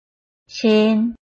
臺灣客語拼音學習網-客語聽讀拼-詔安腔-鼻尾韻
拼音查詢：【詔安腔】cin ~請點選不同聲調拼音聽聽看!(例字漢字部分屬參考性質)